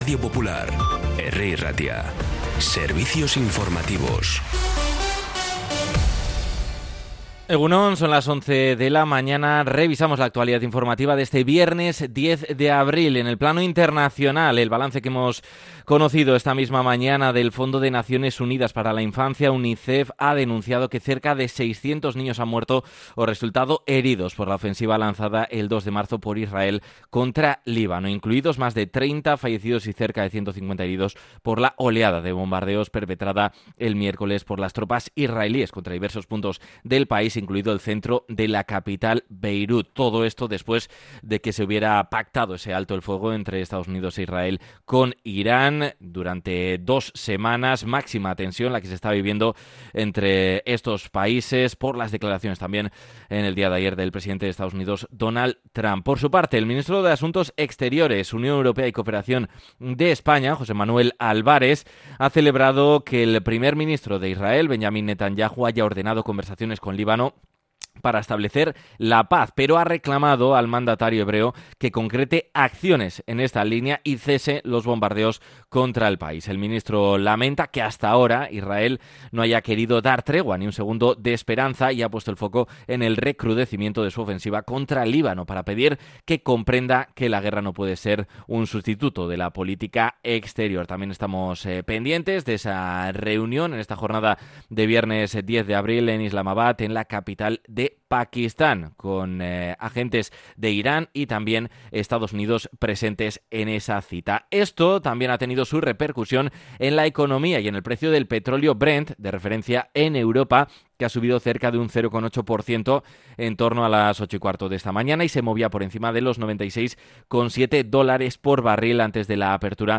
La redacción de informativos de Radio Popular – Herri Irratia trabaja durante todo el día para ofrecerte el resumen informativo más compacto.
Los titulares actualizados con las voces del día. Bilbao, Bizkaia, comarcas, política, sociedad, cultura, sucesos, información de servicio público.